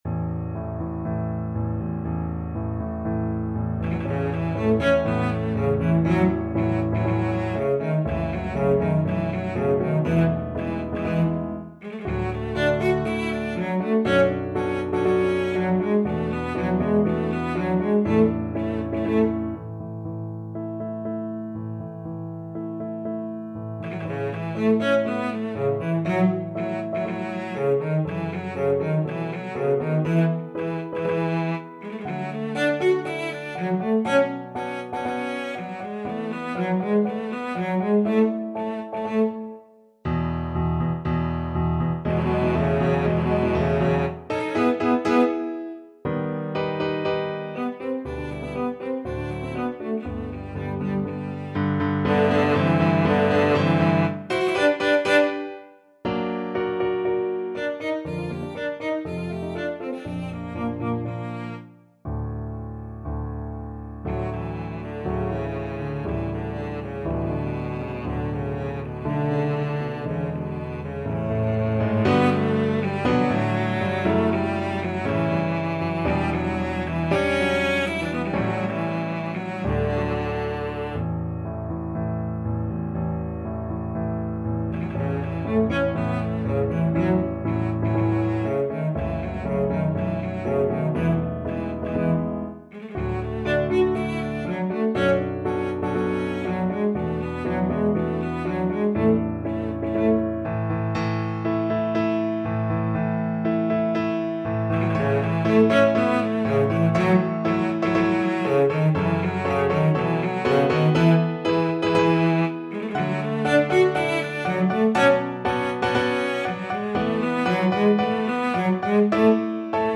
Cello
~ = 120 Tempo di Marcia un poco vivace
4/4 (View more 4/4 Music)
A major (Sounding Pitch) (View more A major Music for Cello )
Classical (View more Classical Cello Music)